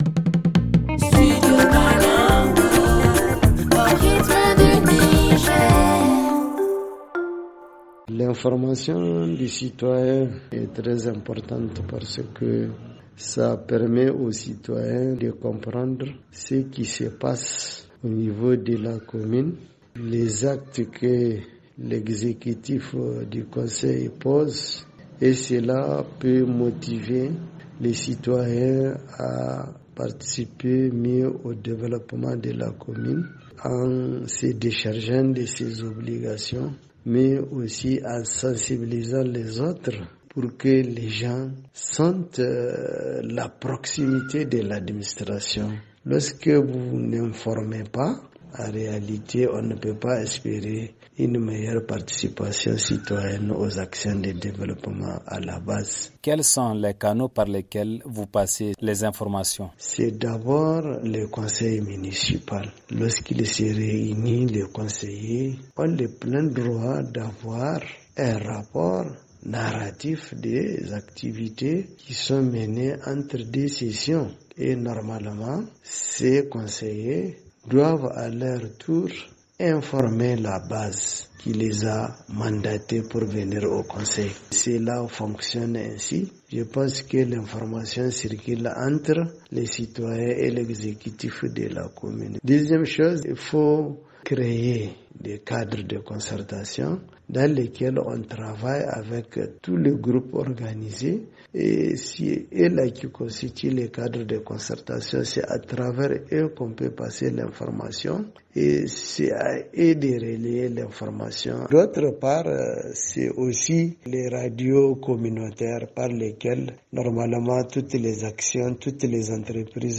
Le magazine en français